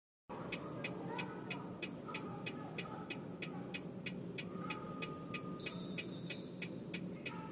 Щелчки (тикание) во время переговорной связи
В некоторых случаях во время переговорной связи в динамике могут наблюдаться
тикающие звуки.
По линии "Л1" как раз вместе с питанием передаются пакеты с данными, которые и слышны как щелчки.
ticks.mp3